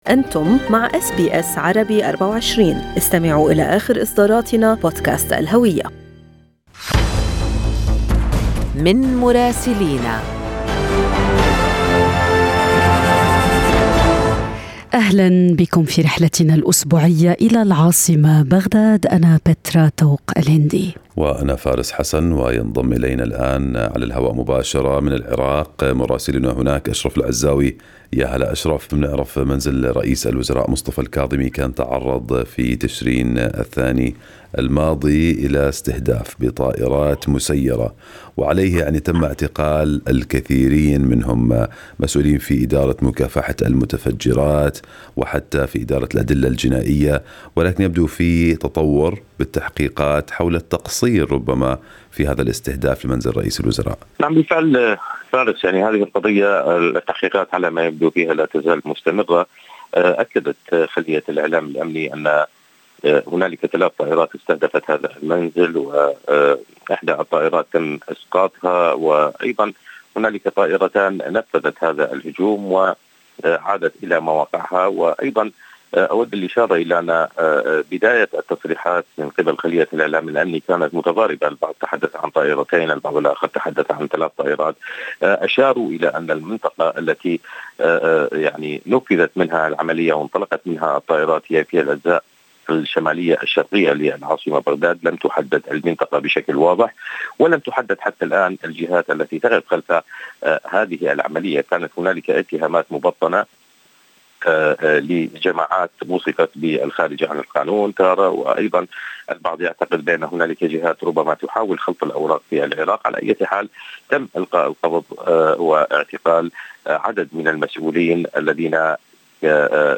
يمكنكم الاستماع إلى تقرير مراسلنا في بغداد بالضغط على التسجيل الصوتي أعلاه.